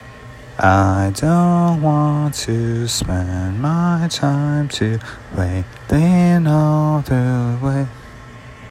FAST STRUM